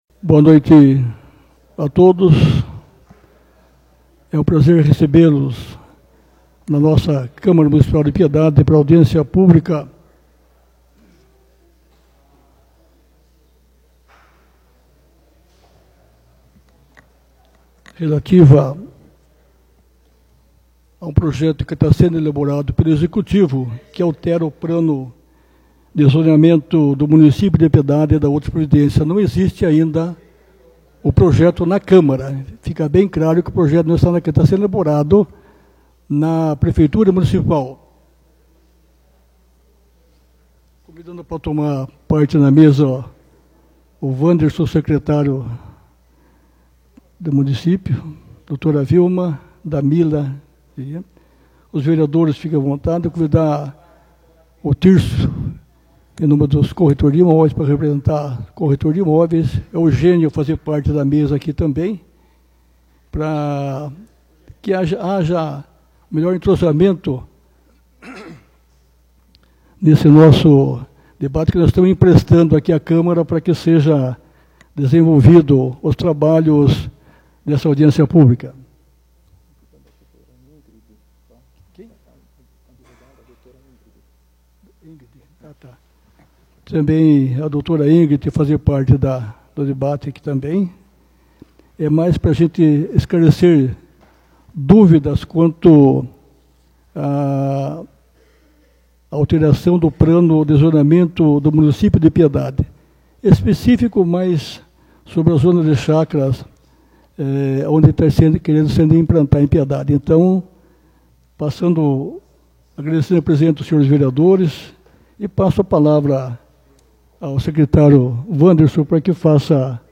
Audiência Pública sobre Alteração do Plano de Zoneamento de Piedade